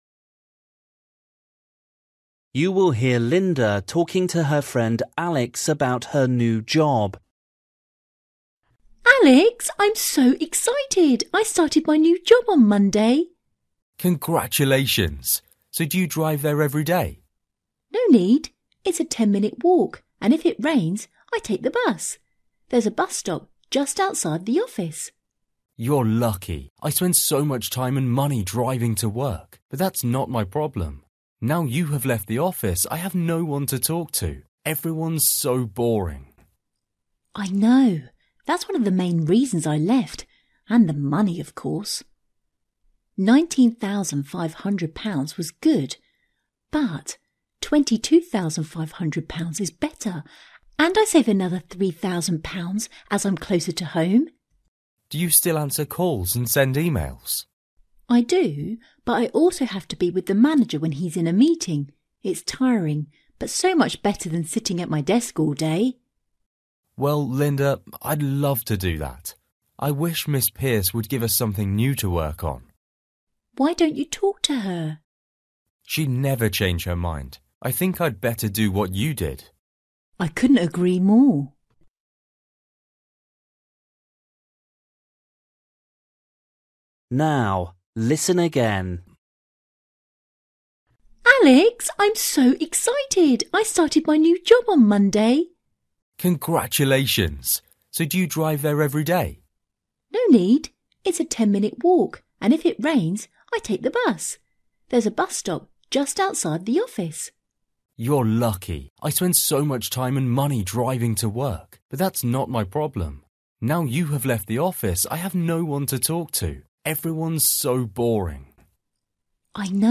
Bài tập trắc nghiệm luyện nghe tiếng Anh trình độ sơ trung cấp – Nghe một cuộc trò chuyện dài phần 27